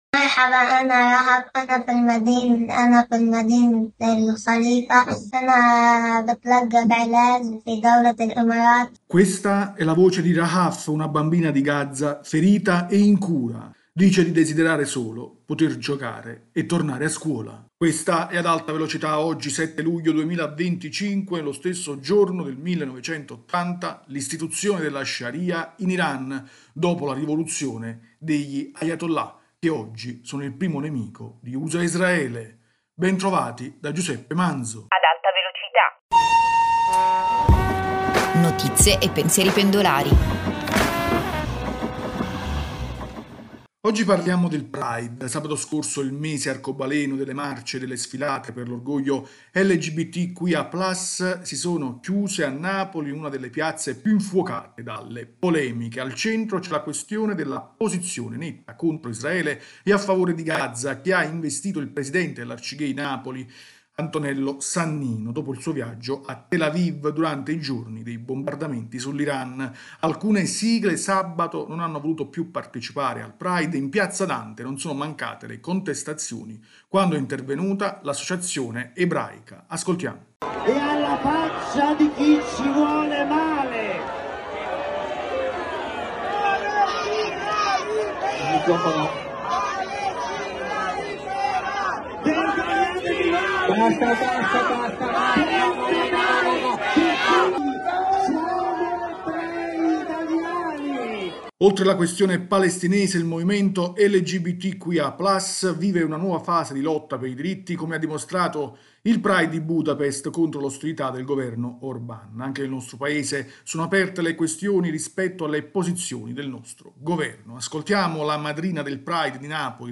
Ascoltiamo la madrina del pride di Napoli la cantante Gaia e alcune voci dei manifestanti.